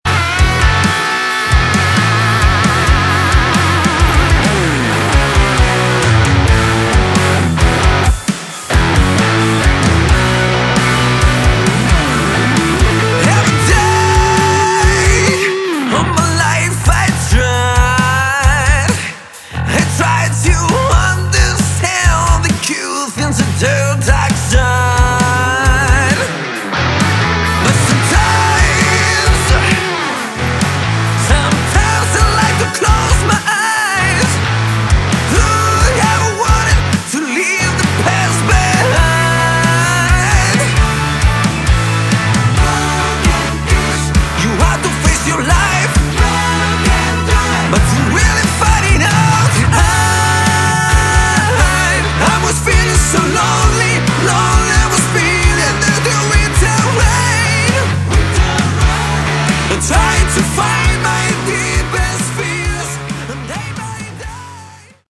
Category: Hard Rock / AOR / Prog